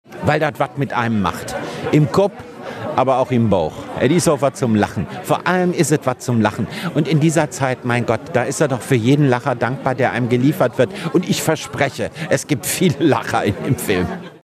Wenn weißer Schnee auf den roten Teppich fällt, dann schimmert der rosa "und rosa gefällt mir", sagte Kerkeling unserem Reporter.